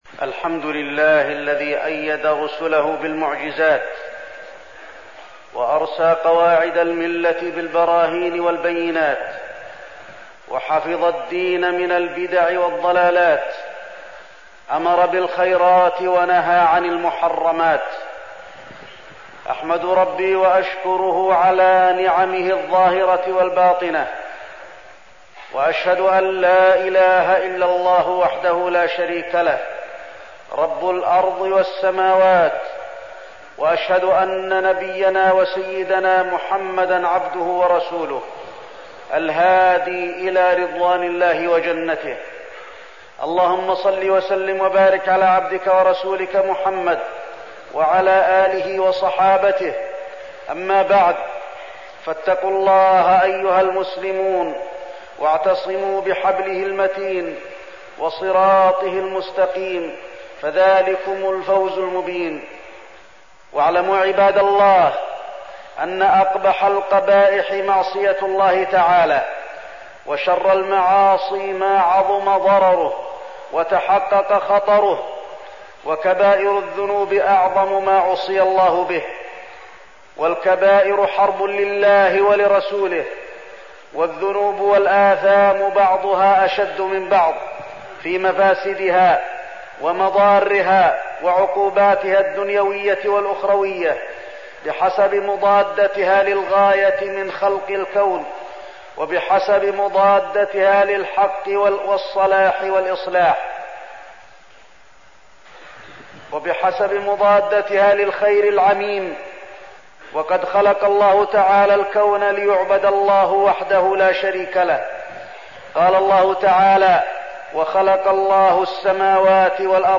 تاريخ النشر ١٨ جمادى الأولى ١٤١٨ هـ المكان: المسجد النبوي الشيخ: فضيلة الشيخ د. علي بن عبدالرحمن الحذيفي فضيلة الشيخ د. علي بن عبدالرحمن الحذيفي السحر والوقاية منه